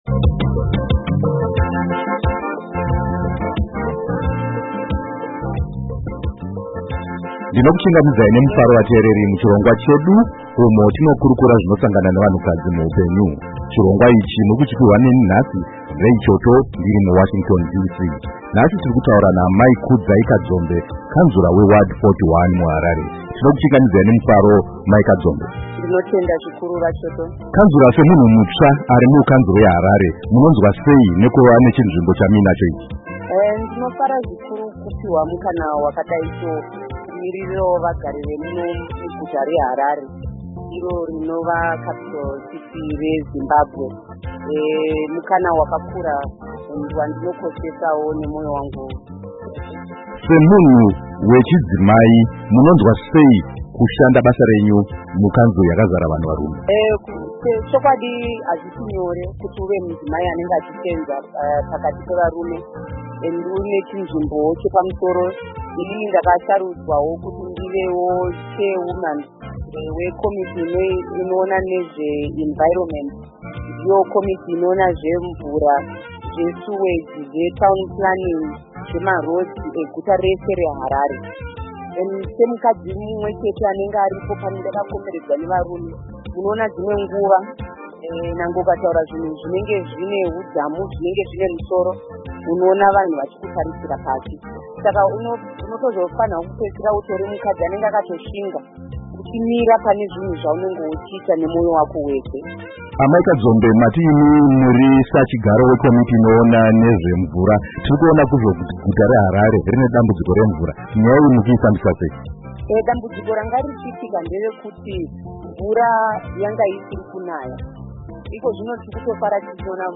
Hurukuro naAmai Kudzai Kadzombe